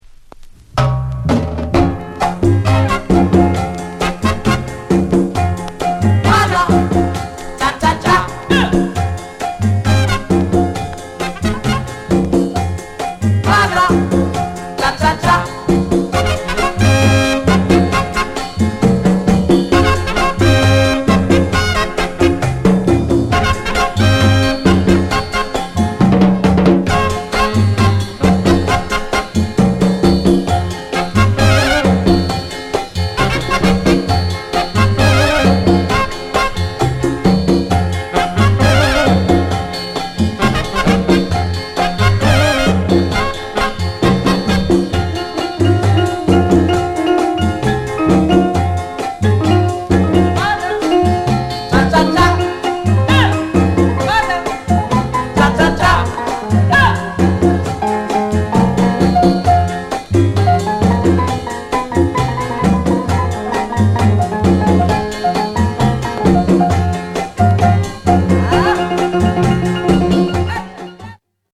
CALYPSO